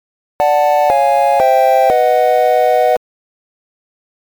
Download Video Game sound effect for free.
Video Game